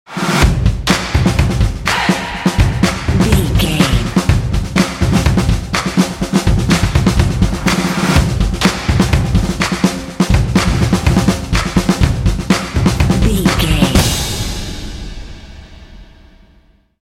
This epic drumline will pump you up for some intense action.
Epic / Action
Atonal
driving
motivational
percussion
vocals
drumline